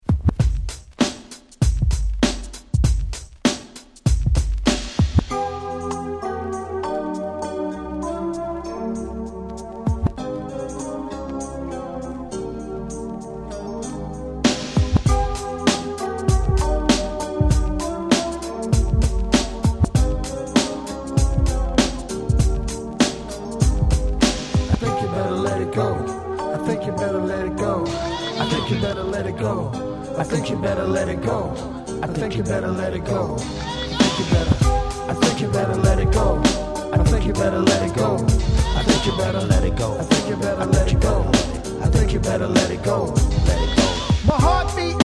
98' Big Hit Hip Hop !!!!!
90's キャッチー系